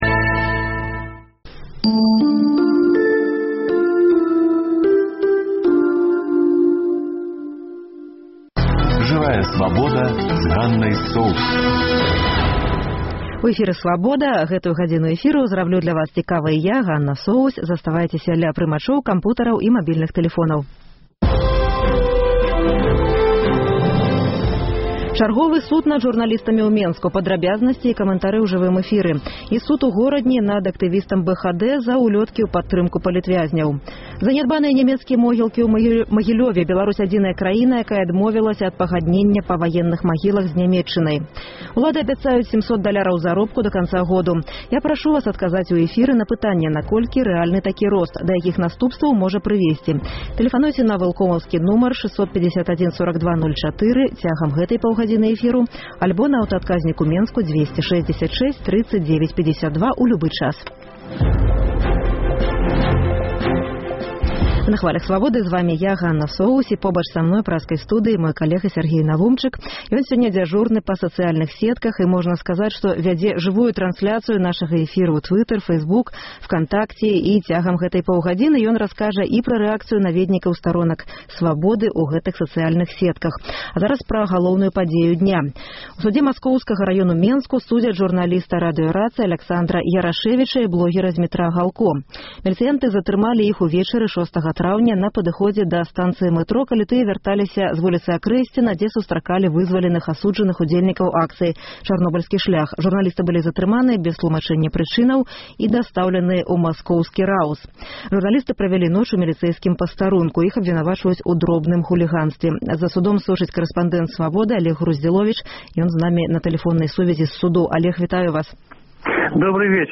Таксама ў жывым эфіры: Чарговы суд над журналістамі — падрабязнасьці і камэнтары. Суд у Горадні над актывістам БХД за ўлёткі ў падтрымку палітвязьняў. Новыя заявы Аляксандра Лукашэнкі — пра помнік, цішыню і палац Незалежнасьці.